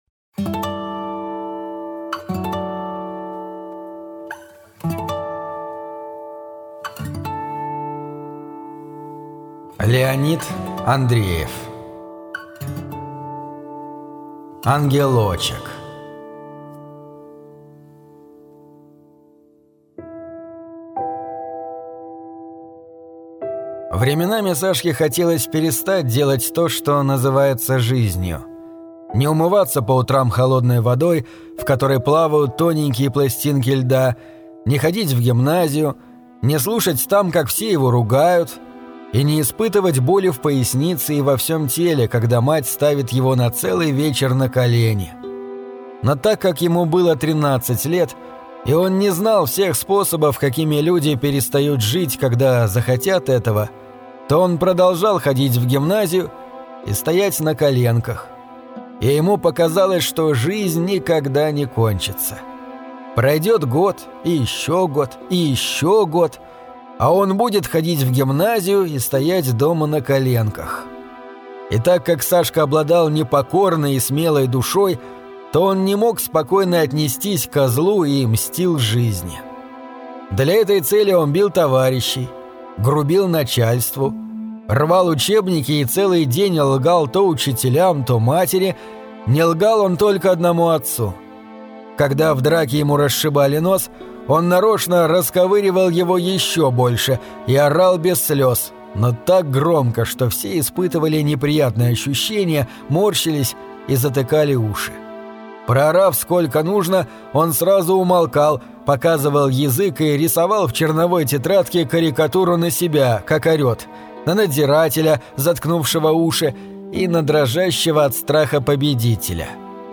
Ангелочек - аудио рассказ Андреева Л.Н. Рассказ про тринадцатилетнего мальчика Сашу, который не любил жизнь, казавшейся ему сплошной мукой.